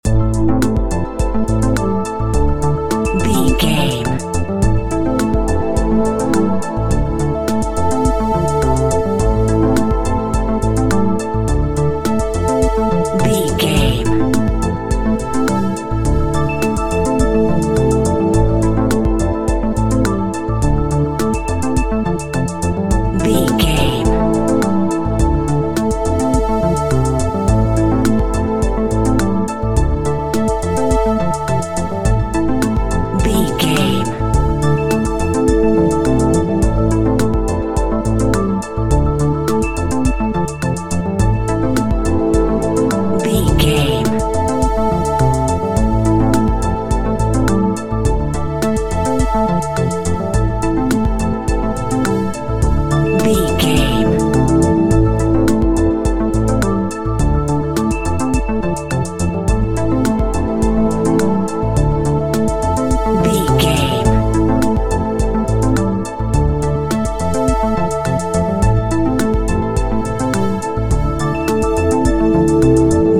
Future Computer Music.
Aeolian/Minor
futuristic
hypnotic
dreamy
drum machine
synthesiser
ambient
electronic
downtempo
pads
synth lead
synth bass